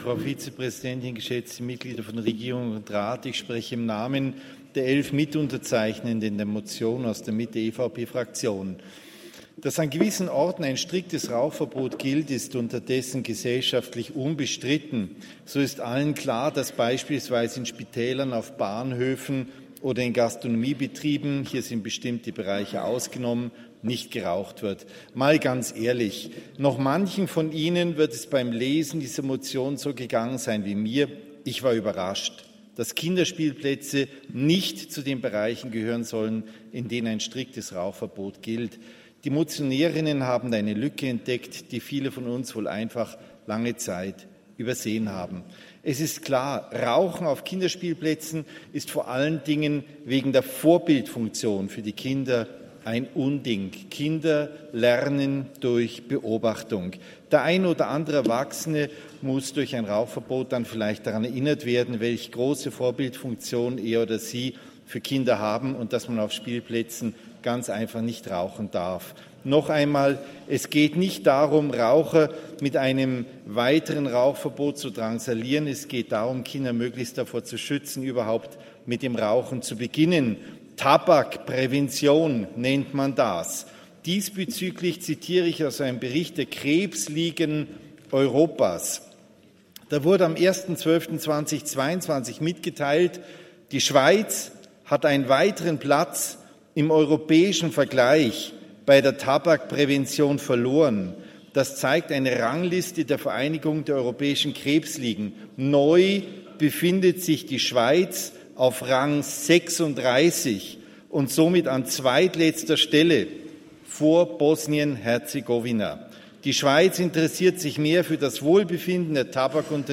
Session des Kantonsrates vom 27. bis 29. November 2023, Wintersession
27.11.2023Wortmeldung